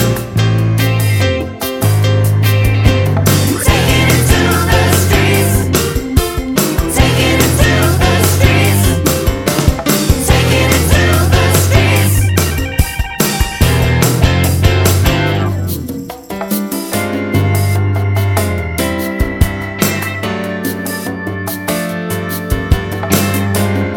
Pop (2000s)